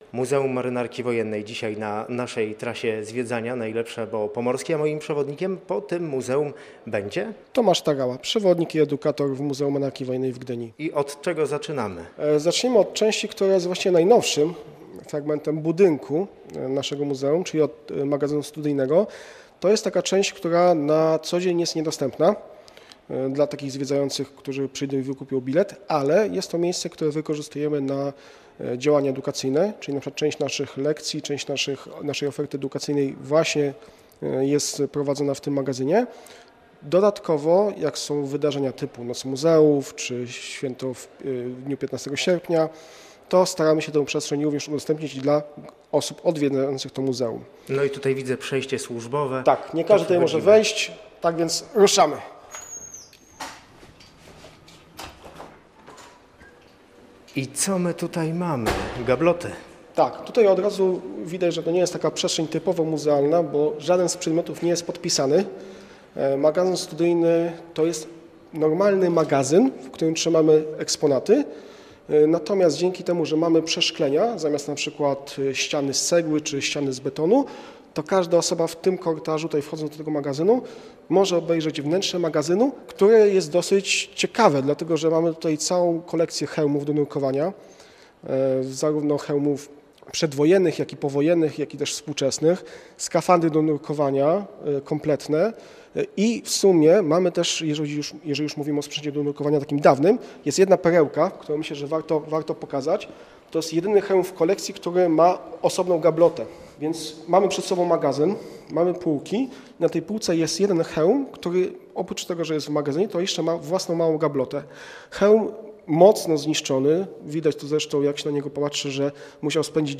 Do Muzeum Marynarki Wojennej zabrał słuchaczy Radia Gdańsk